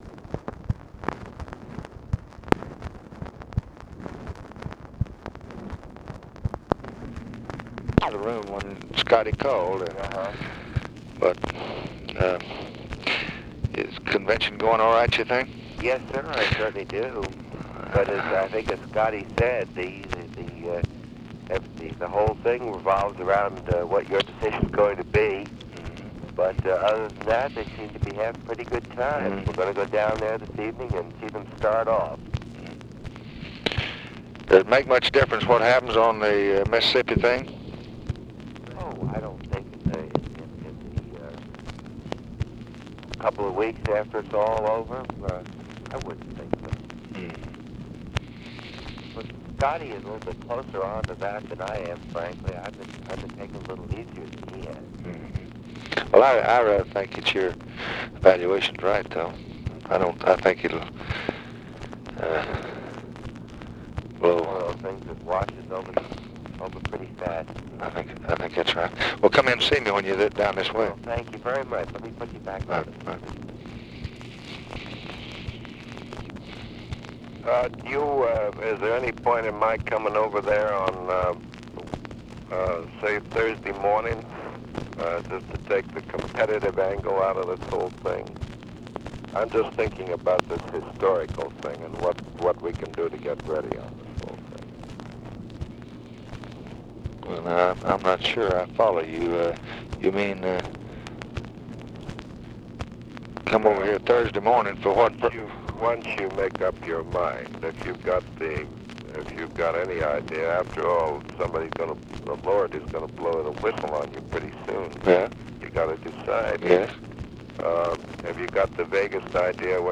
Conversation with JAMES RESTON, August 24, 1964
Secret White House Tapes